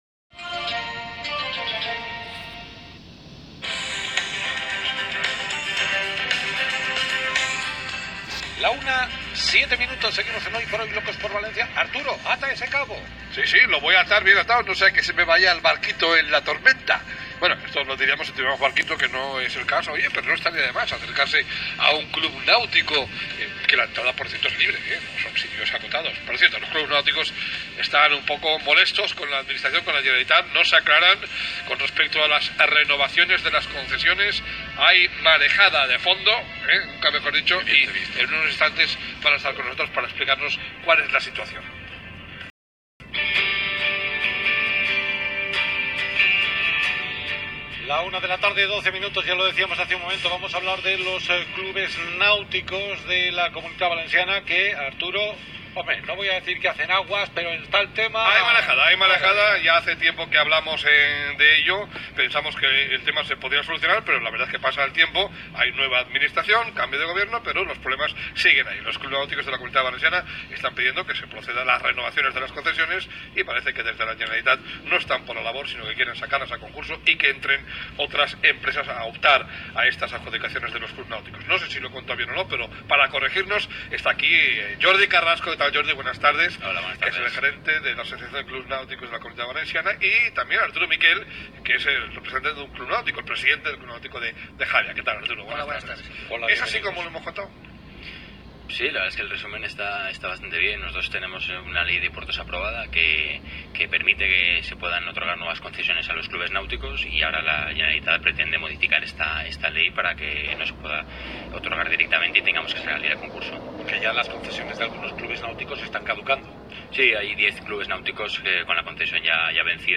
Entrevista en la Cadena SER – ACNCV – asociación de clubs náuticos de la comunidad valenciana
25-nov-Entrevista-Cadena-Ser-Locos-Por-Valencia.m4a